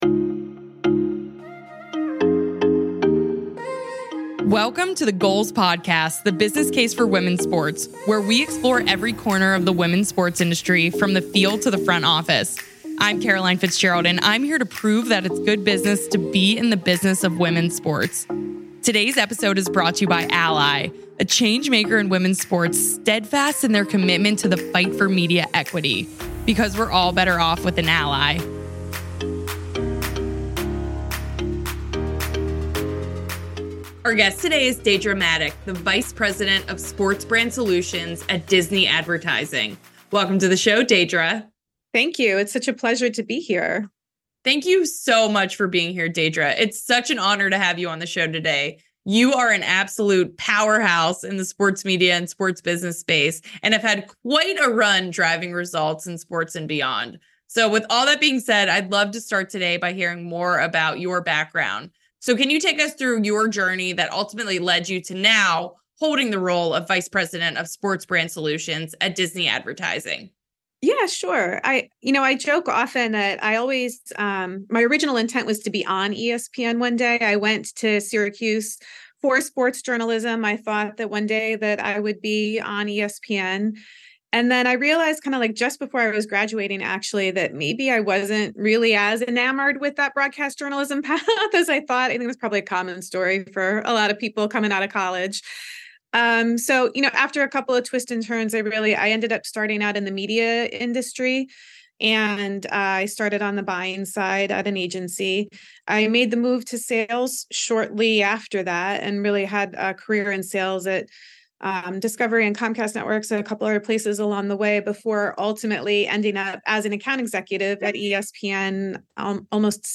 Download - Ep. #109 A Conversation About Women, Money & Sports, ft.